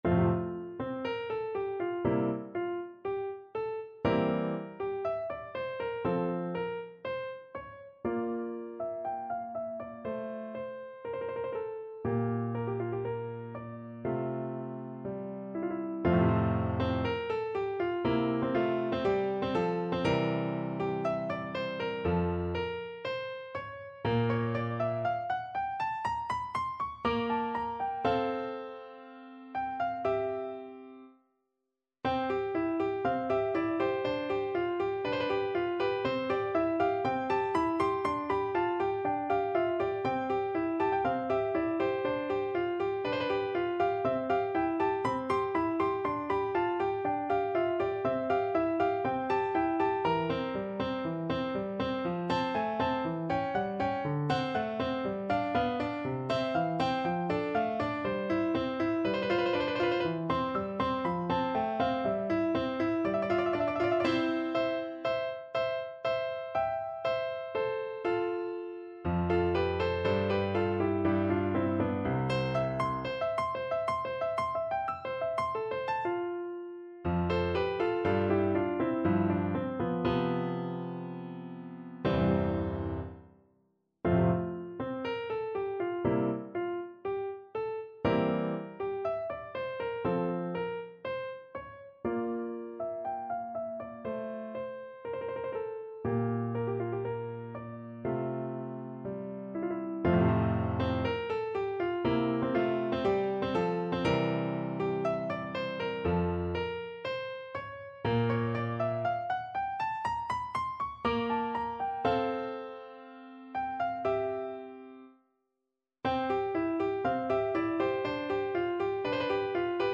Free Sheet music for Piano
No parts available for this pieces as it is for solo piano.
2/2 (View more 2/2 Music)
Adagio
F major (Sounding Pitch) (View more F major Music for Piano )
Instrument:
Classical (View more Classical Piano Music)